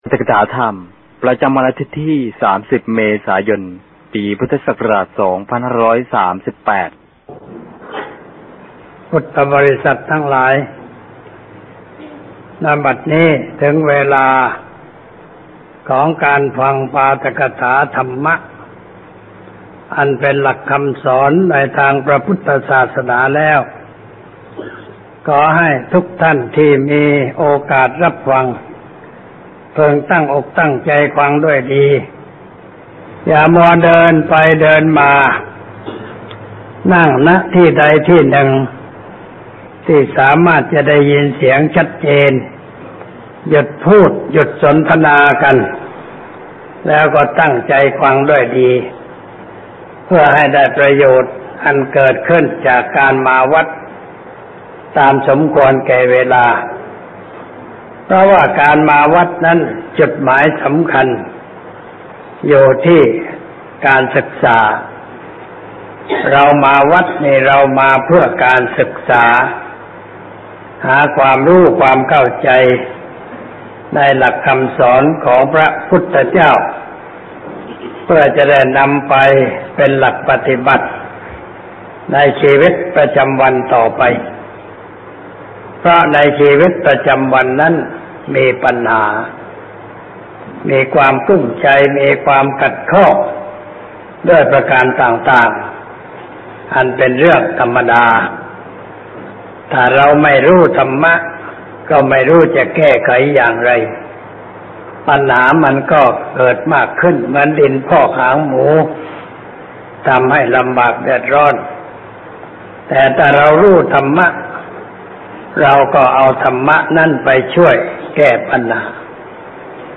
พุทธบริษัททั้งหลาย ณ บัดนี้ถึงเวลาของการฟังปาฐกถาธรรมะ อันเป็นหลักคำสอนในทางพระพุทธศาสนาแล้ว ขอให้ทุกท่านที่มีโอกาสรับฟังจงตั้งอกตั้งใจฟังด้วยดี อย่ามัวเดินไปเ ...